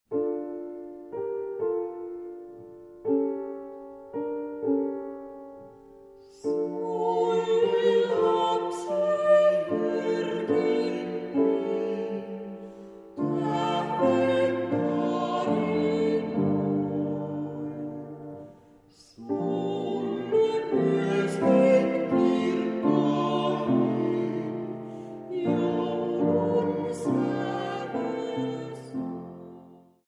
Nuorten laulamia joululauluja yksinlaulu- ja kuorosovituksina.